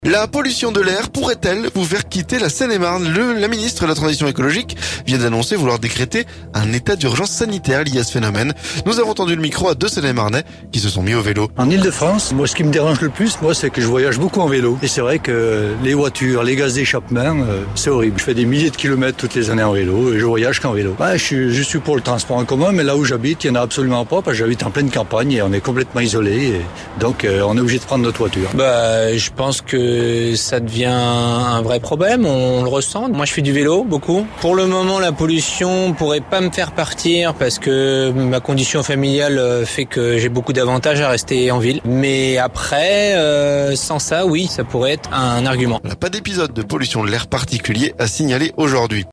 La ministre de la transition écologique vient d'annoncer vouloir décréter un état d'urgence sanitaire liée à ce phénomène. Nous avons tendu le micro à deux Seine-et-marnais qui se sont mis au vélo.